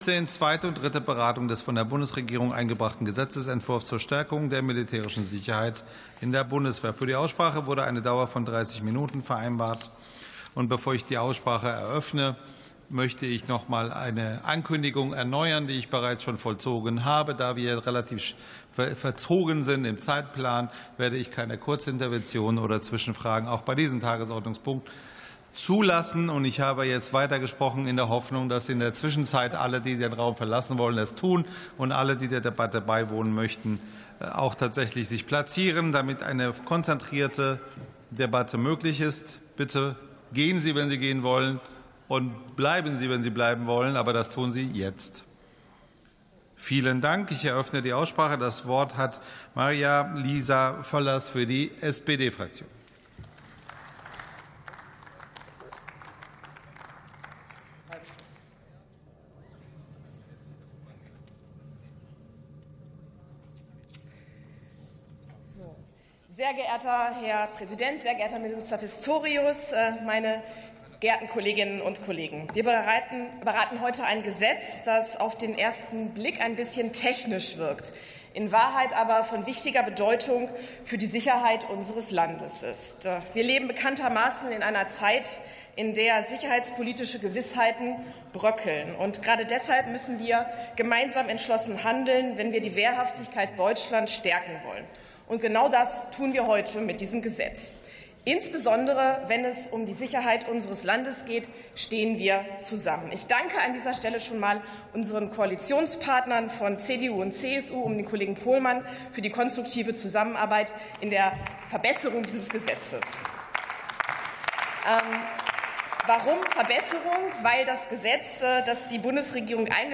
47. Sitzung vom 04.12.2025. TOP 15: Militärische Sicherheit in der Bundeswehr ~ Plenarsitzungen - Audio Podcasts Podcast